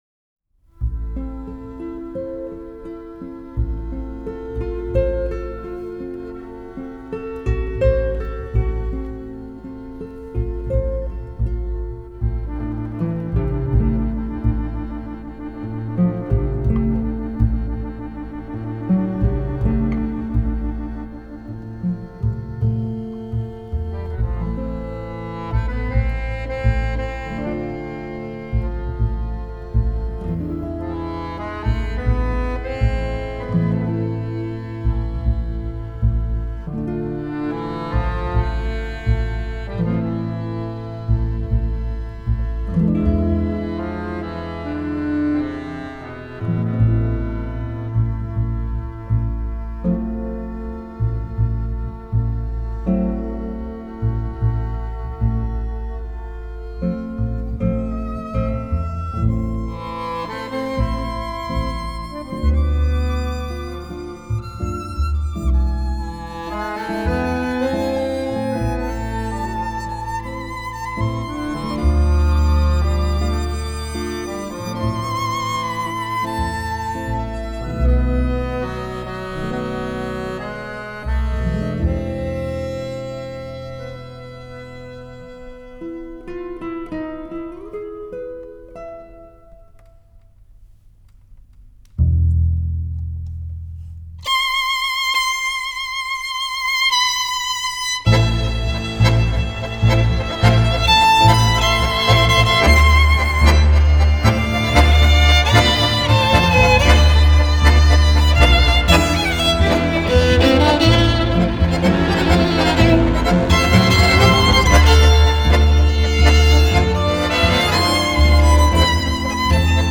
который состоял из баяна, скрипки, гитары и контрабаса.